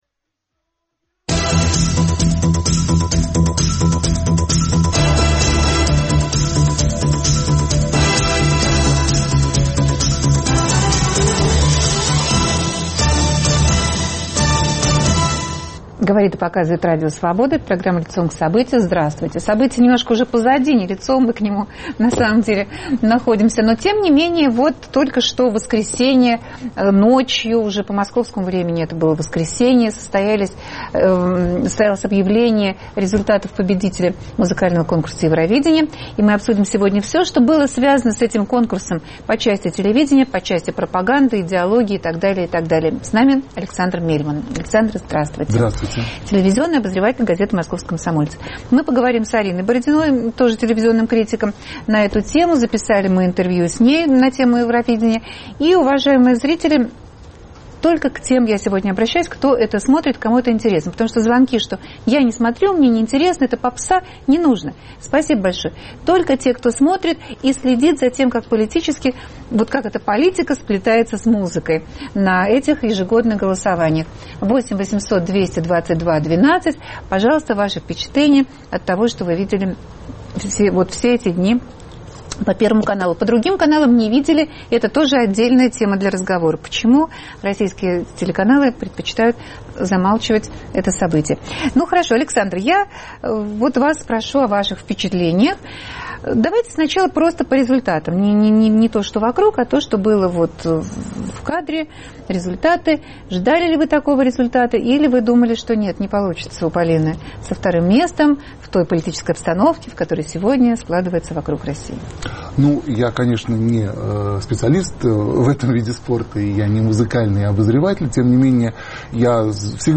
В гостях телекритики